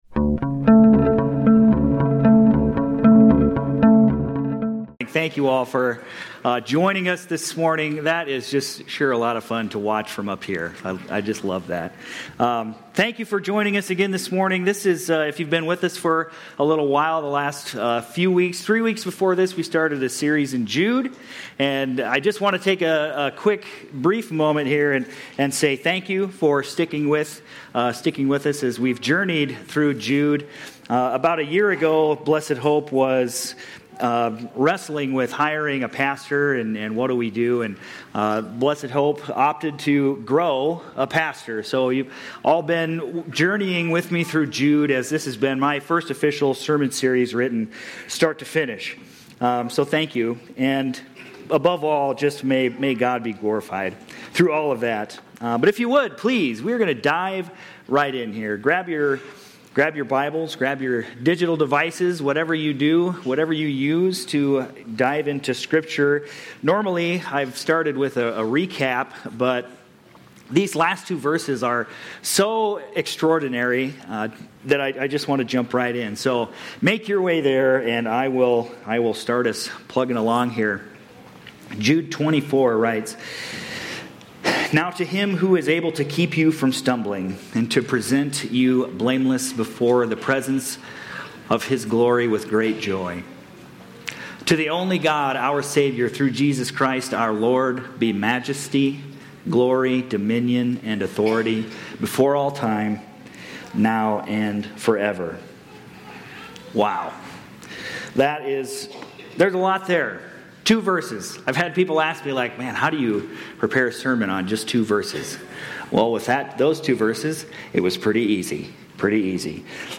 Aug-31-25-Sermon-Audio.mp3